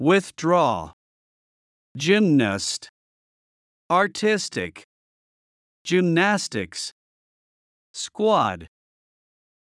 音声を再生し、強勢のある母音（＝大きな赤文字）を意識しながら次の手順で練習しましょう。